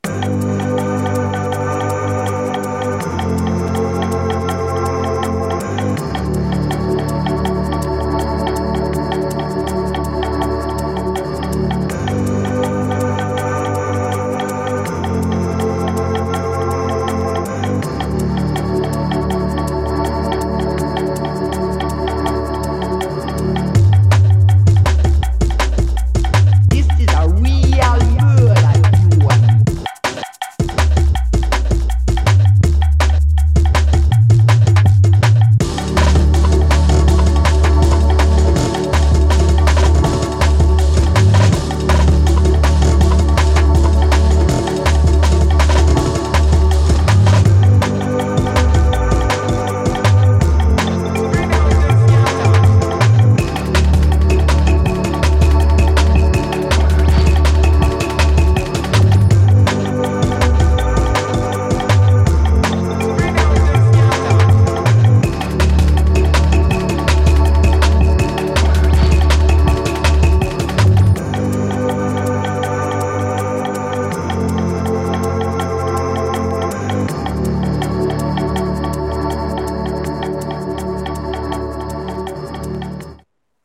downloaded some jungle sample CDs and did some playing around on the p6, i dropped all the files on my mc-101’s SD card so now its acting as my portable storage as well. trying to experiment with genres i dont normally tackle